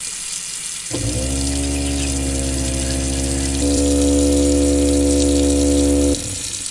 斯密特厨房 " 水槽垃圾处理器
描述：在Zoom H4n上录制96 KHz 32位立体声
标签： 厨房 国内的声音 现场记录 器具 烹饪